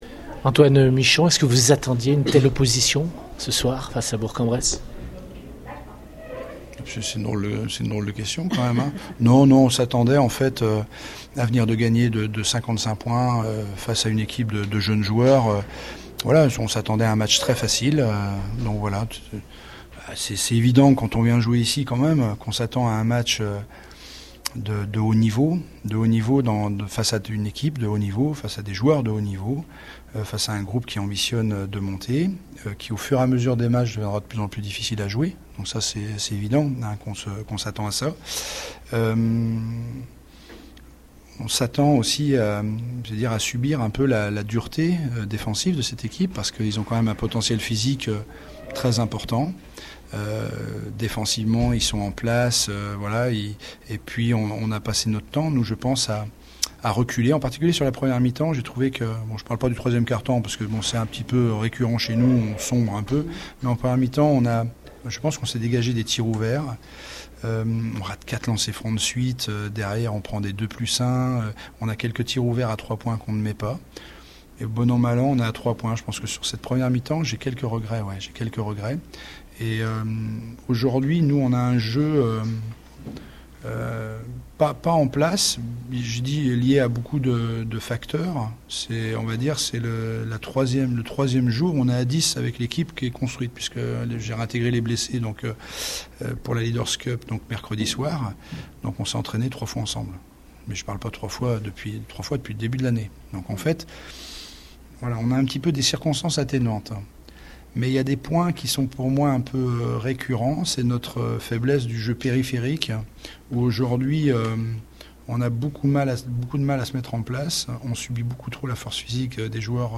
On écoute les réactions d’après-match au micro Radio Scoop